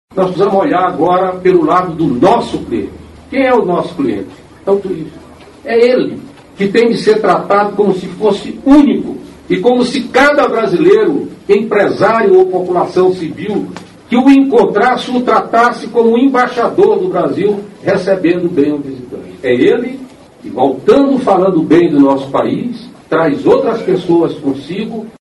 aqui para ouvir declaração do ministro Gastão Vieira sobre a importância do bom atendimento a visitantes no país.